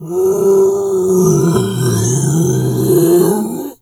bear_roar_01.wav